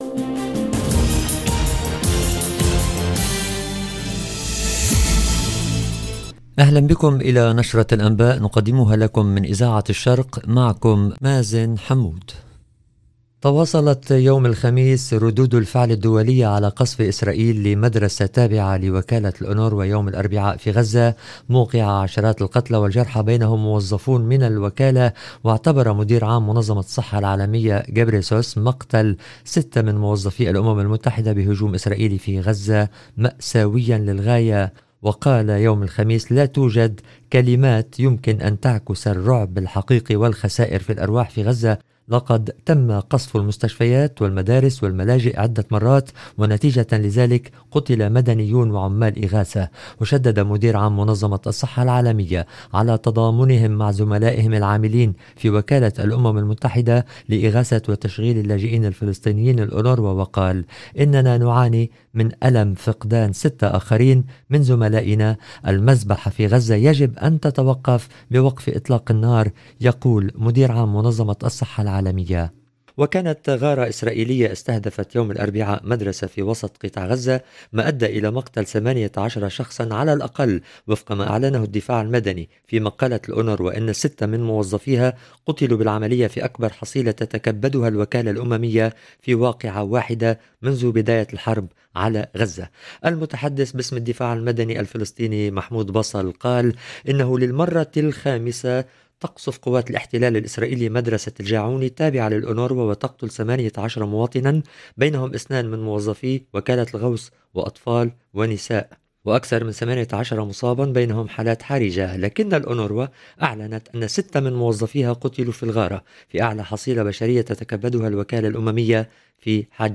LE JOURNAL EN LANGUE ARABE DU SOIR DU 12/09/24